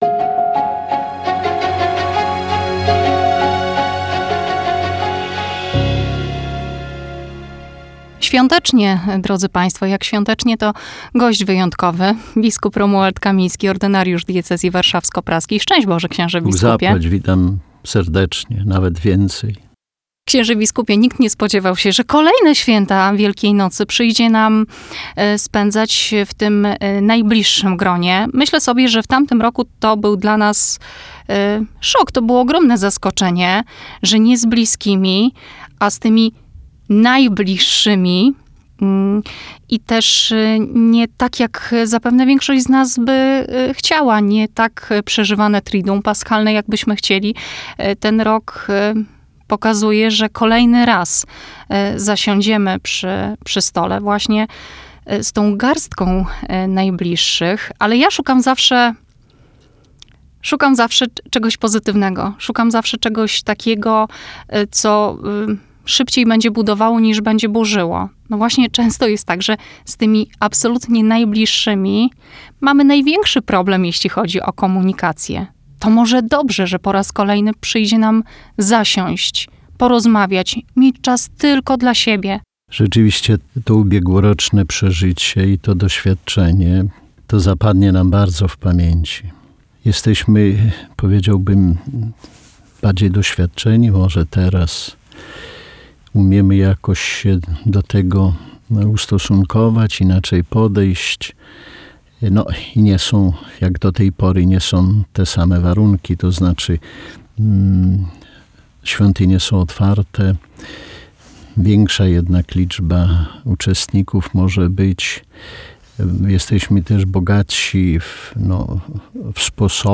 rozmowa_z_biskupem.mp3